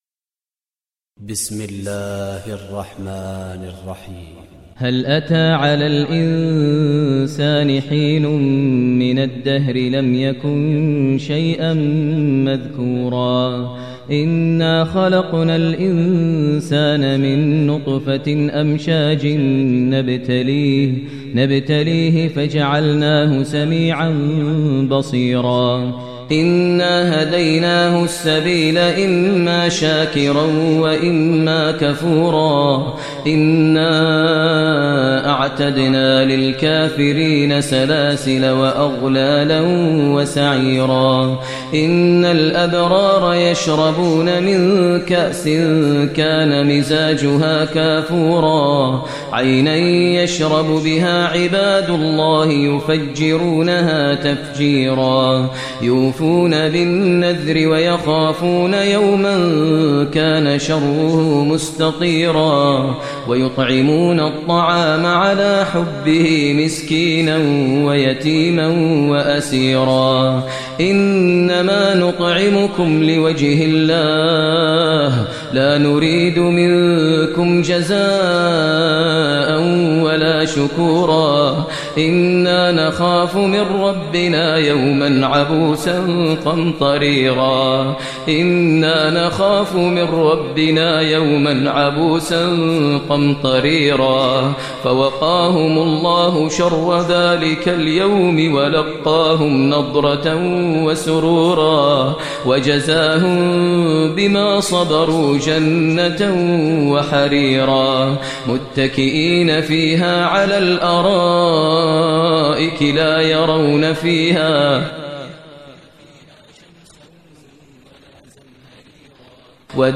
Surah Insan Recitation by Sheikh Maher Mueaqly
Surah Insan, listen online mp3 tilawat / recitation in Arabic recited by Imam e Kaaba Sheikh Maher al Mueaqly.